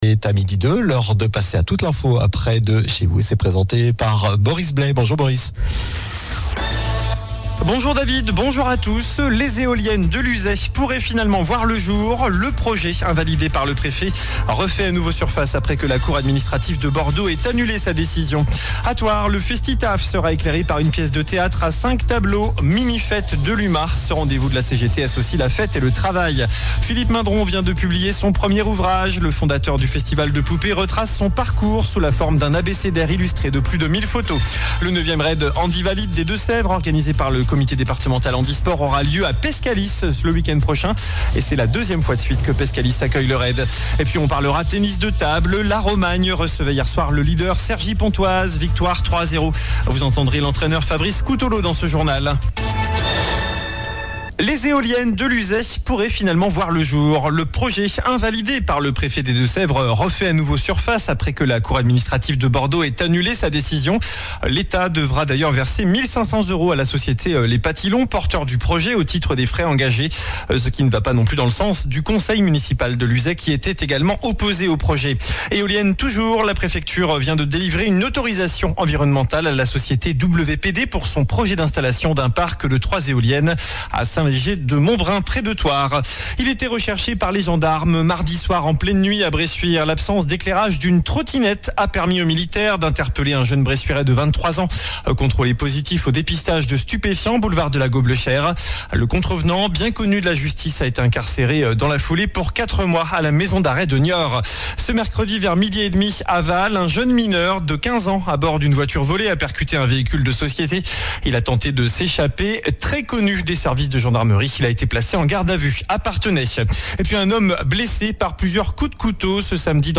Journal du jeudi 25 avril (midi)